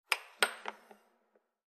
Air Hockey; Puck Hits And Bounce On Table.